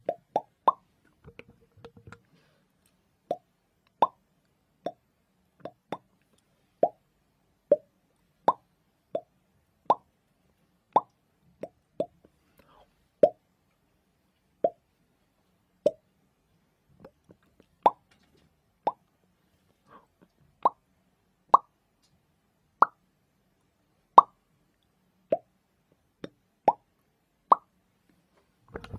Pop up Bubble
animation bubble explode pop sound effect free sound royalty free Sound Effects